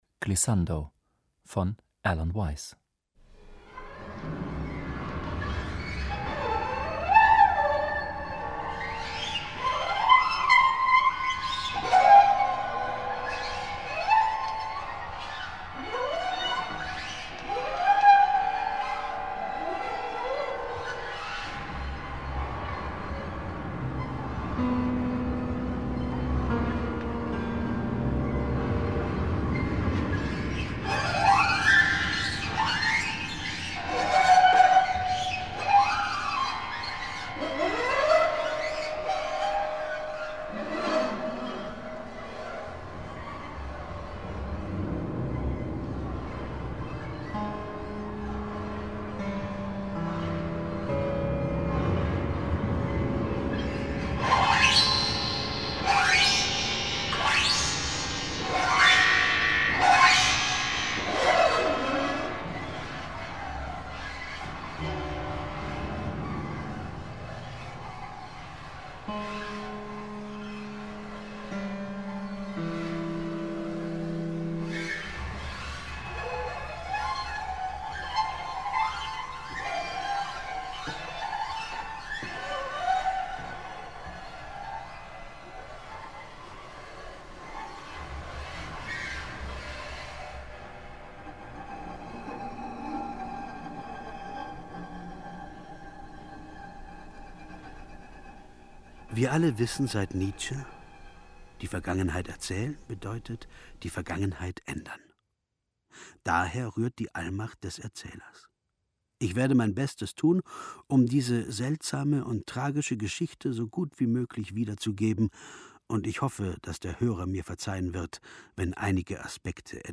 Experimentelles Radio